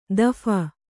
♪ daphana